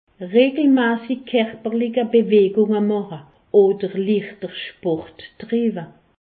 Haut Rhin
Pfastatt